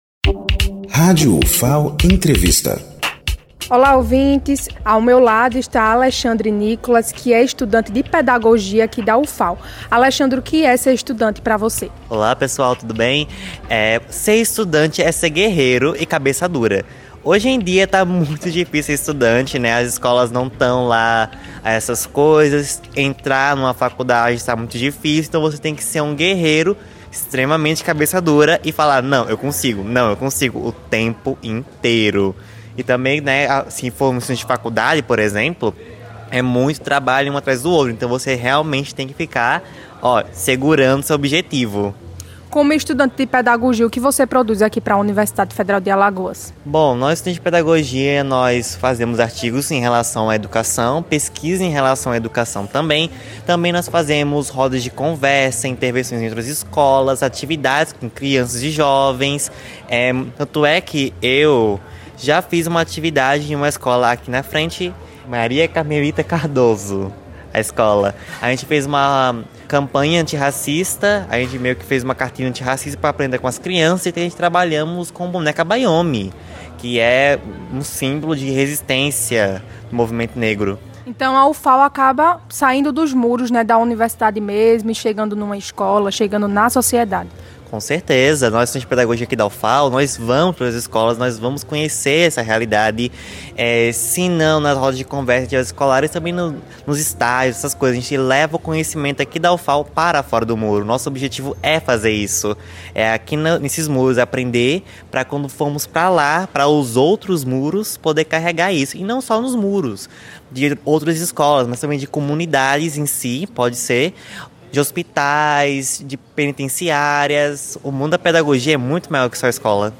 Alunos dos cursos Pedagogia e Química da Ufal compartilham as conquistas e os desafios do dia a dia como estudantes da Ufal.
Baixar MP3 Entrevistados
Locução